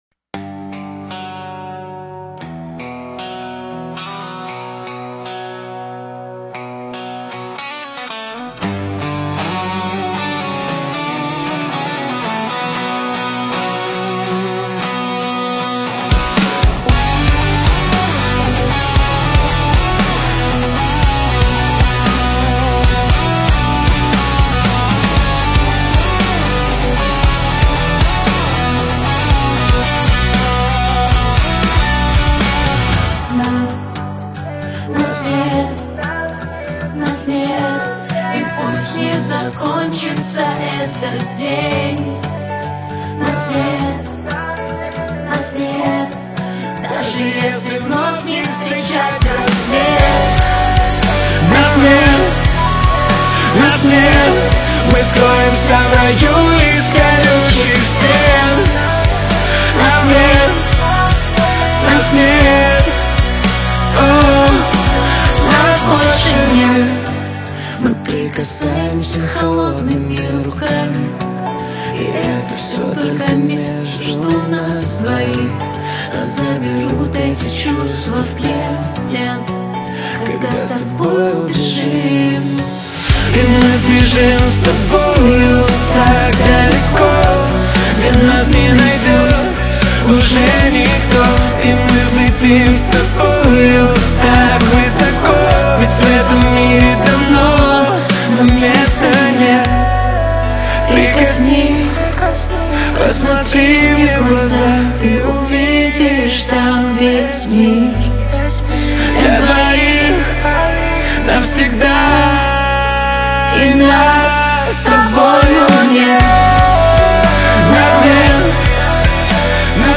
Rock version